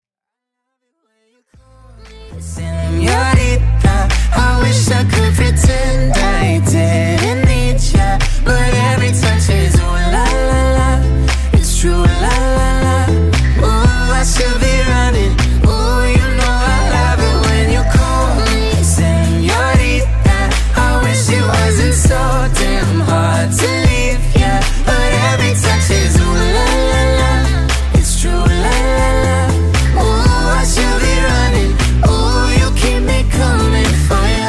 • Качество: 192, Stereo
поп
гитара
дуэт
красивый вокал
романтика